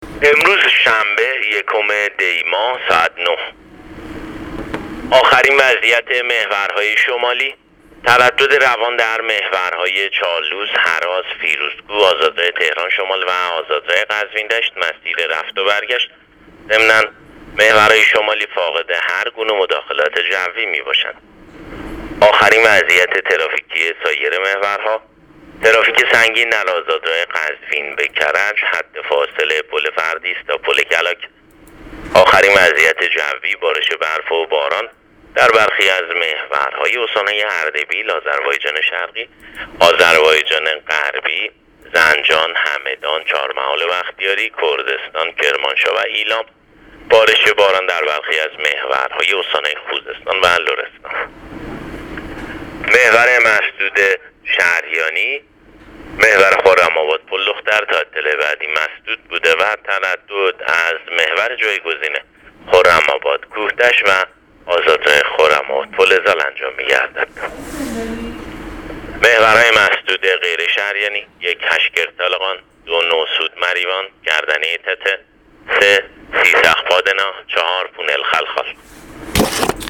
گزارش رادیو اینترنتی از آخرین وضعیت ترافیکی جاده‌ها تا ساعت ۹ یکم آذر؛